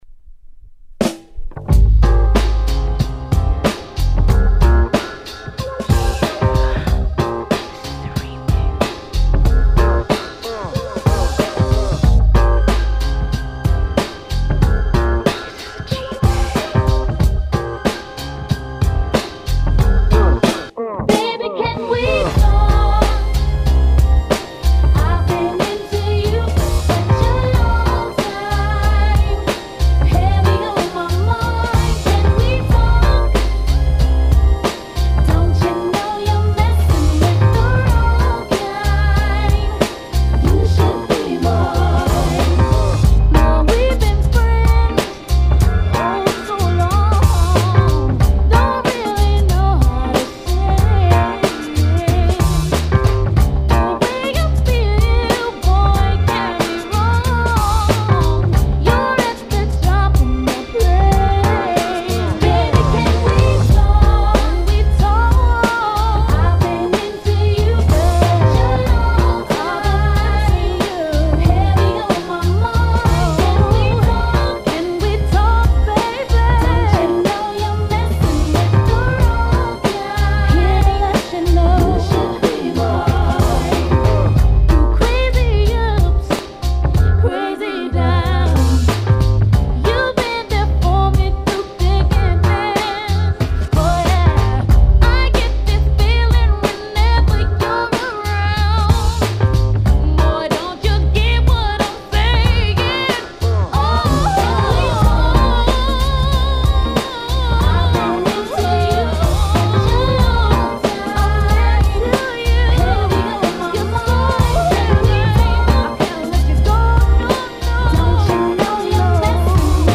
説明不要の90s R&B CLASSICSの人気リミックスVerに定番サンプリングソースをブレンド！！（片面プレス）